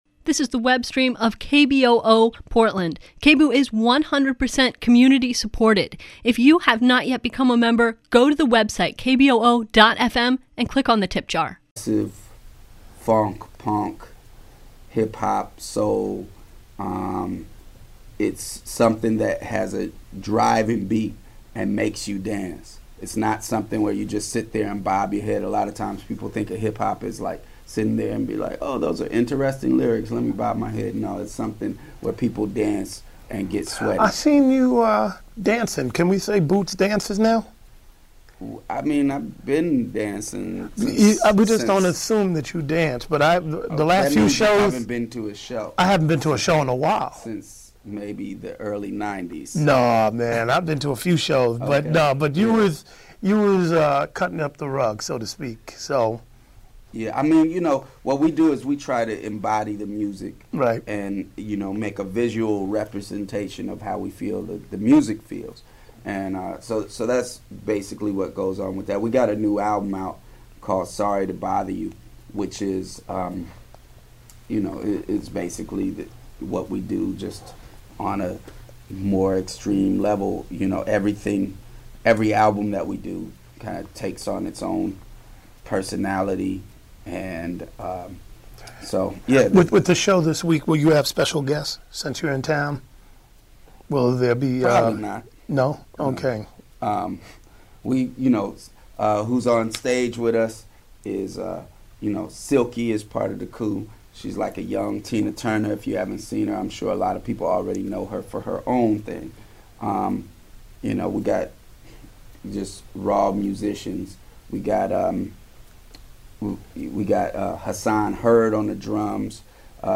Download audio file The Santa Cruz, CA based non-profit, Article V Convention for Our Children’s Future (AVC4OCF), announced, today, the date of their first national, radio/internet town-hall meeting in a planned series of similar events that they are calling their National Campaign for Job...